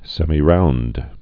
(sĕmē-round, sĕmī-)